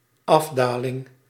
Ääntäminen
IPA: [ˈapfaːɐt] IPA: /ˈapˌfaːɐ̯t/